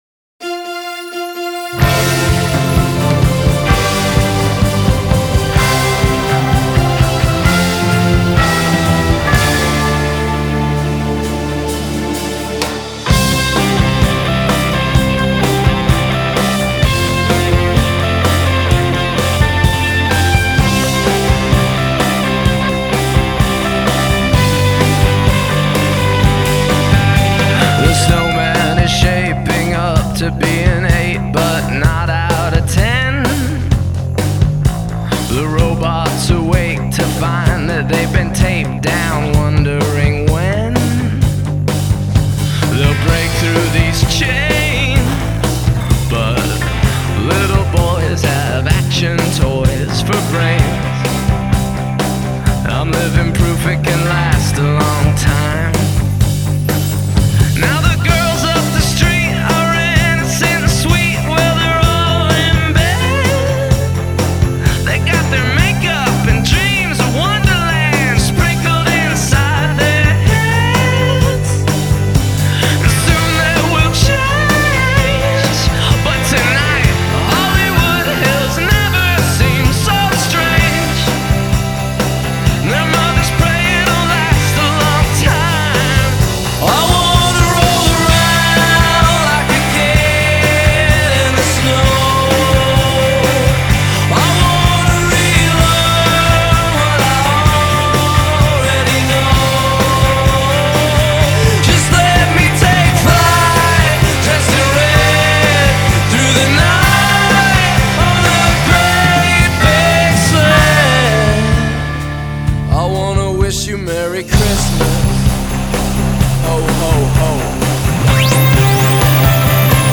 Genre: Indie Rock, Pop-Rock